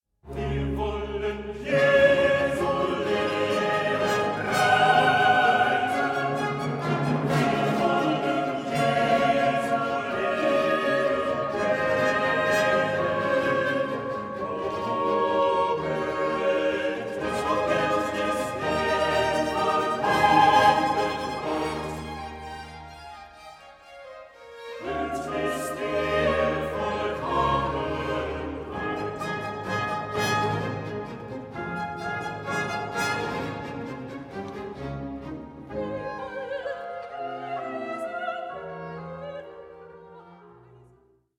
Stereo
17 Coro